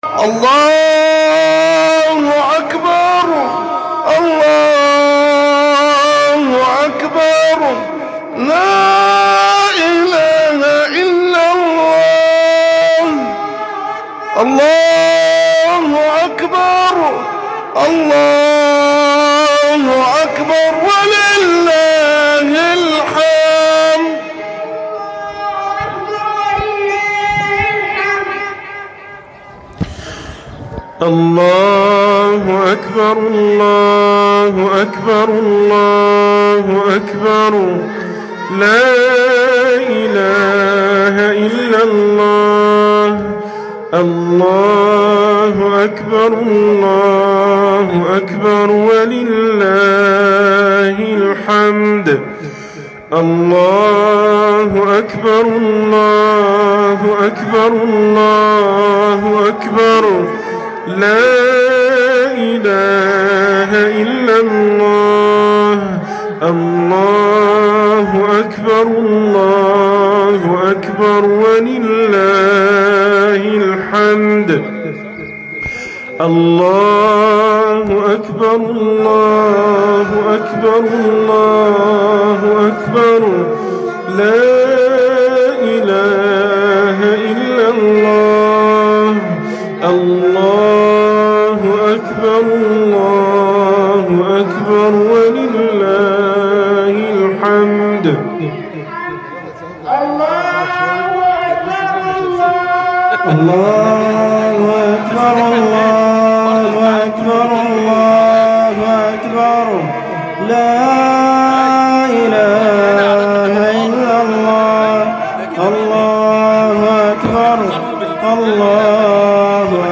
خطبة عيد الفطر المبارك 1446 - عَلَامَاتُ قَبُولِ الْعَمَلِ
خطبة عيد الفطر المبارك 1446 هـ~1.mp3